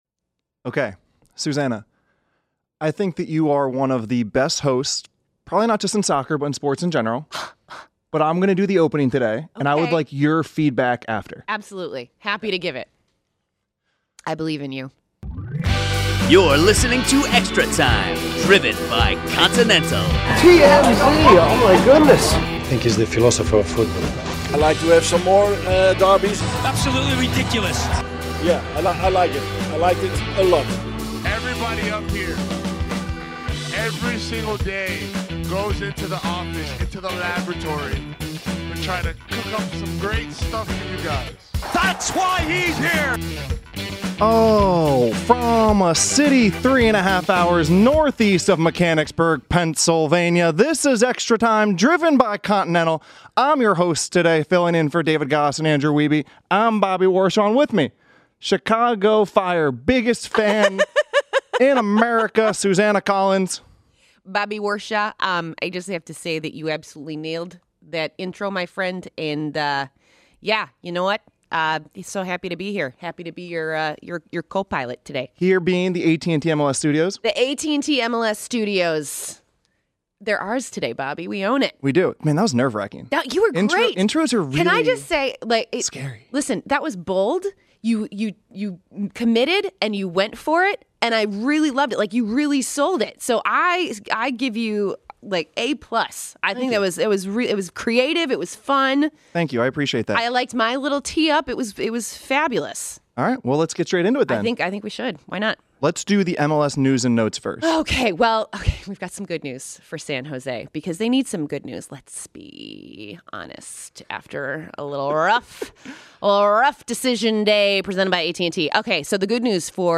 Former U.S. National Team midfielder and Colorado Rapids player and coach Pablo Mastroeni joins the studio crew to detail how he would prepare for a playoff game, who are his picks for success in the Audi 2019 MLS Cup Playoffs and let us all know what he has been up to!
Gregg Berhalter interview